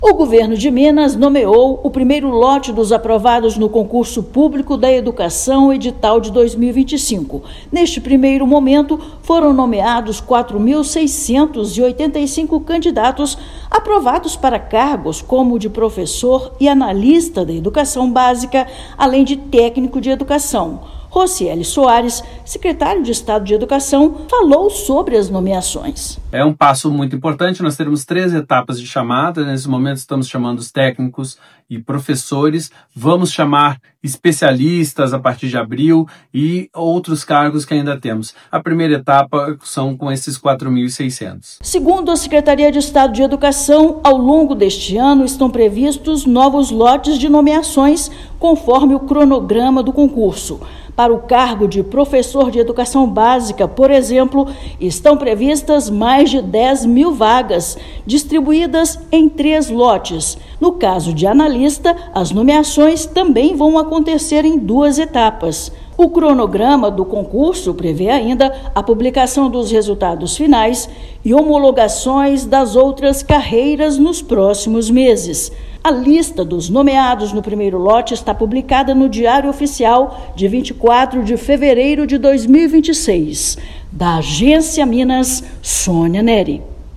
Publicação desta terça-feira (24/2) no Diário Oficial integra cronograma estruturado de nomeações ao longo do ano de 2026. Ouça matéria de rádio.